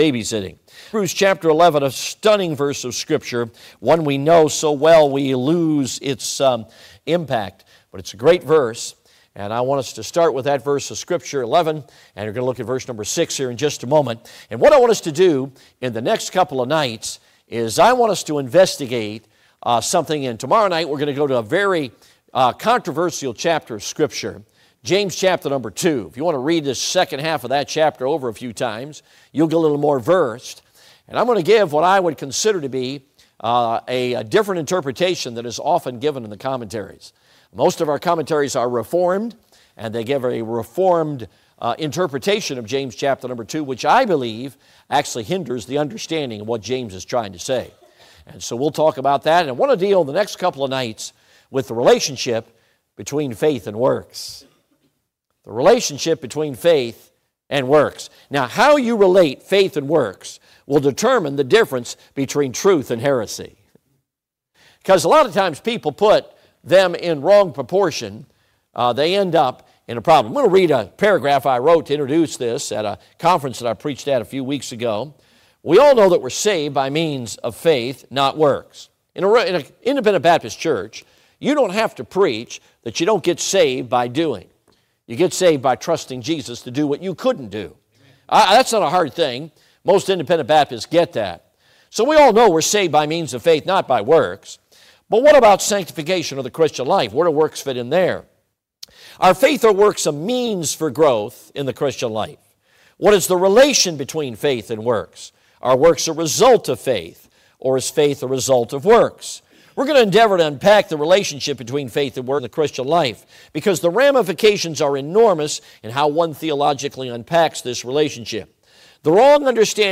Heb. 11:6 Service Type: Midweek Service Topics